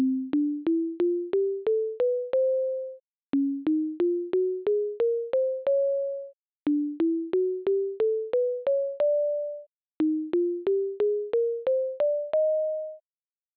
Here are some sample melodies produced by the program.
FourScales.au